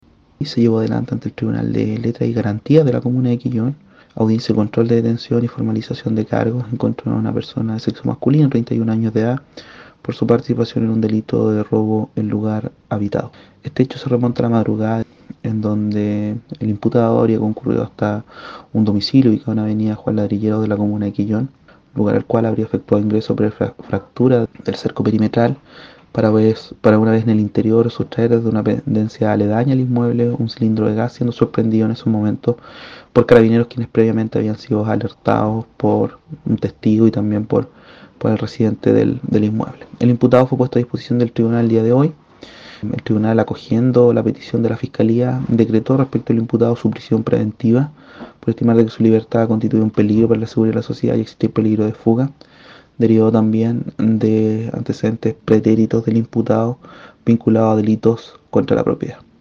El acusado, según el relato de la fiscalía, en la madrugada del día jueves 30 de mayo, acudió hasta un domicilio de avenida Juan Ladrilleros desde donde sustrajo un cilindro de gas, lo que fue alertado por varias personas que fueron testigos del ilícito, señaló el fiscal Fabián Fernández.
31-FISCAL-ROBO-QUELLON.mp3